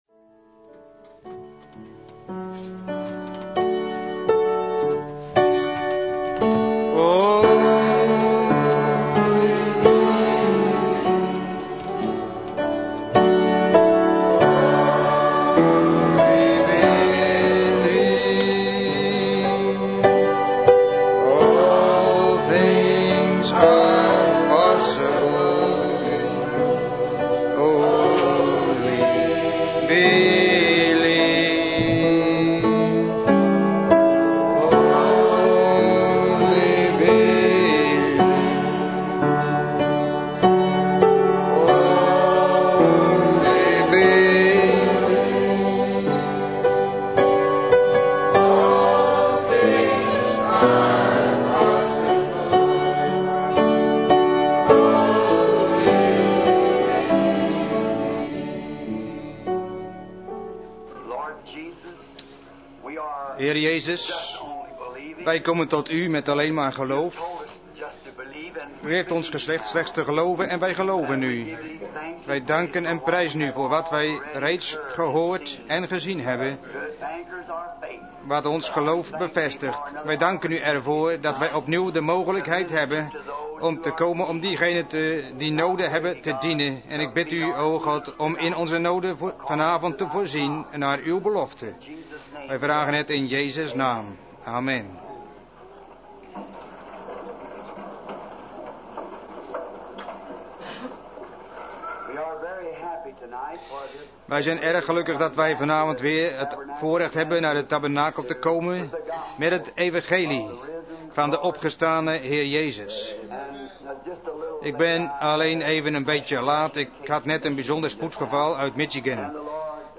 Vertaalde prediking "Desperations" door William Marrion Branham te Branham Tabernacle, Jeffersonville, Indiana, USA, 's avonds op zondag 01 september 1963